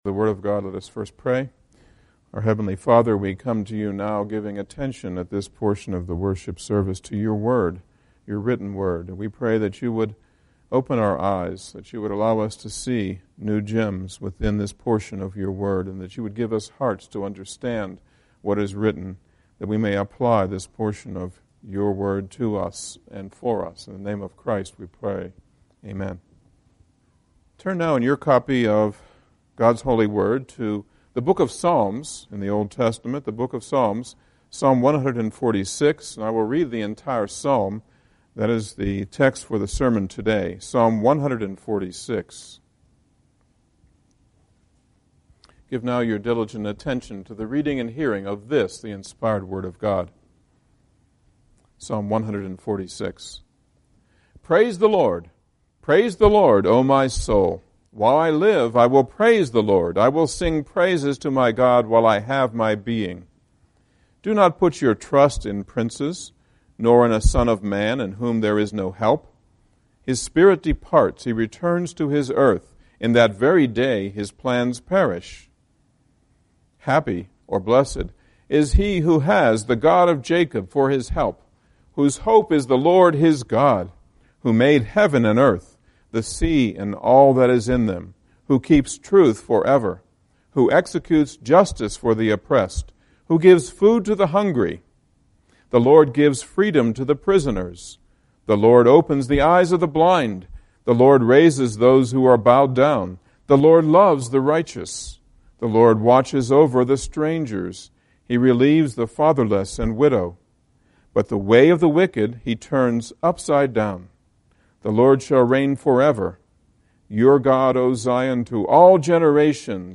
Passage: Psalm 146 Service Type: Sunday Morning Service « One Thing is Needed RAA 5